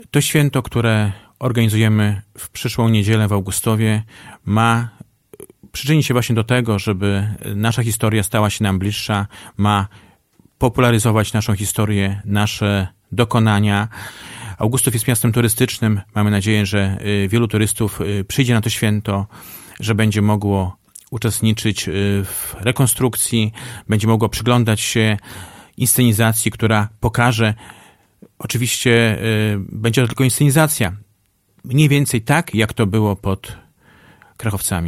O szczegółach mówił we wtorek (18.07) w Radiu 5 Jarosław Szlaszyński, starosta powiatu augustowskiego.